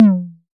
RDM_TapeA_SY1-Tom01.wav